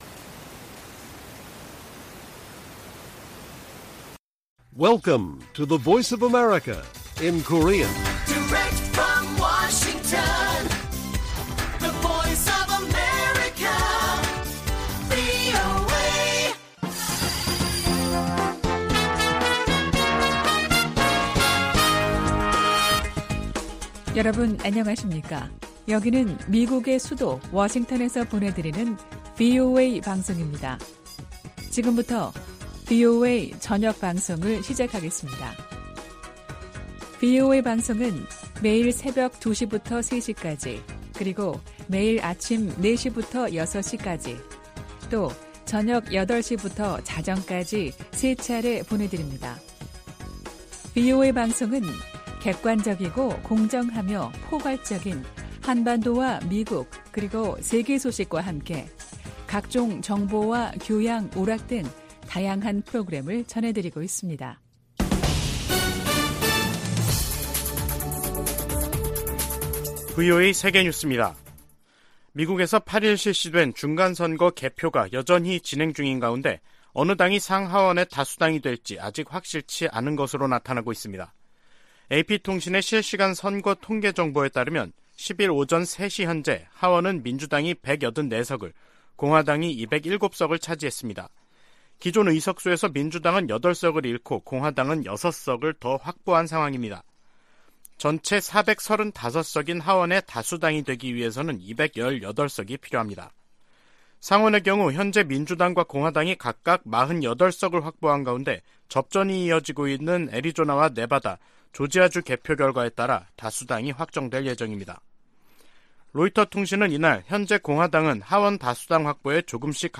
VOA 한국어 간판 뉴스 프로그램 '뉴스 투데이', 2022년 11월 10일 1부 방송입니다. 미국 중간선거 결과 의회 다수당의 변화가 예상되는 가운데 한반도 등 대외 현안들에 어떤 영향을 미칠지 주목됩니다. 미국과 한국, 일본, 중국의 정상들이 G20 정상회의에 참석하면서 북한 문제를 둘러싼 다자 외교전이 펼쳐질 전망입니다. 북한이 핵물질 생산을 위해 영변 핵시설을 지속적으로 가동하고 있다고 전 국제원자력기구(IAEA) 사무차장이 밝혔습니다.